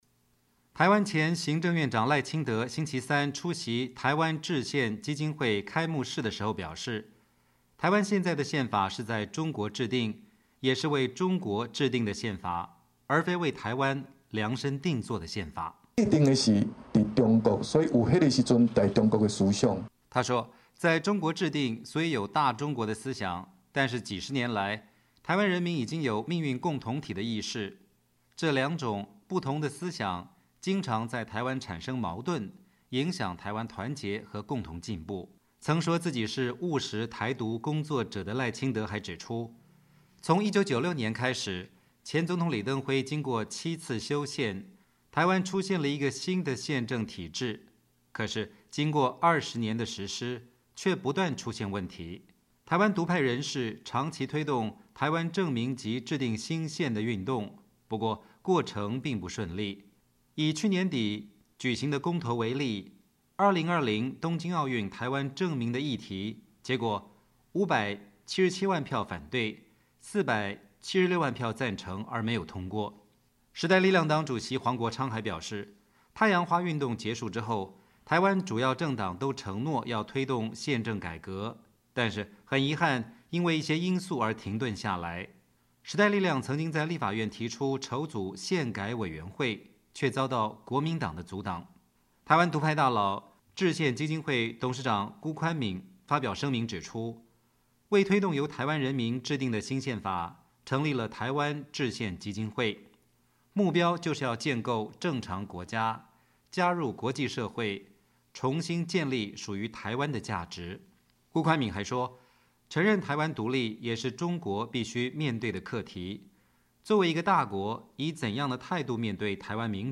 台湾前行政院长赖清德星期三(1月23号)出席台湾制宪基金会开幕式时表示，台湾现在的宪法是在中国制定，也是为中国制定的宪法，而非为台湾量身订做的宪法。
而台下同时也有民众高喊要求民进党说到做到！